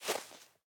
Minecraft Version Minecraft Version latest Latest Release | Latest Snapshot latest / assets / minecraft / sounds / block / powder_snow / step6.ogg Compare With Compare With Latest Release | Latest Snapshot